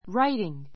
ráitiŋ
（ ⦣ wr- というつながりでは w は発音しない）